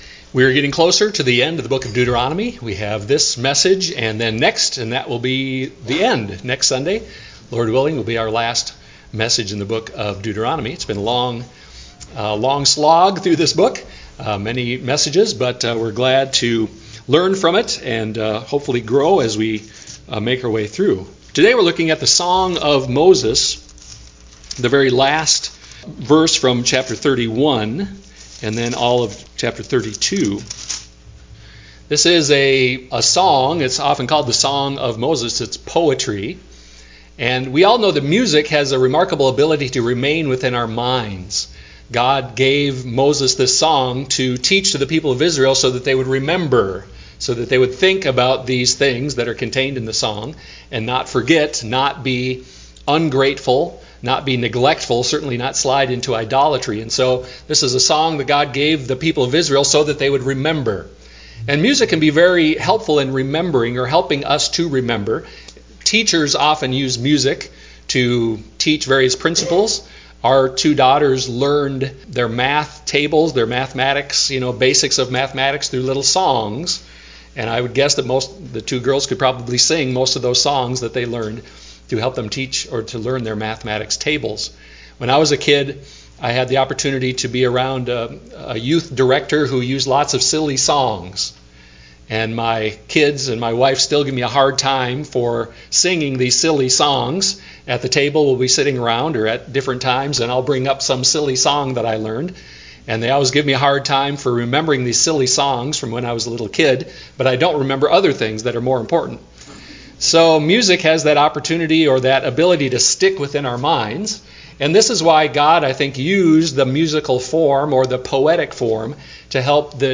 Deuteronomy 32 Service Type: Sunday morning worship service Music has an amazing ability to remain within our minds.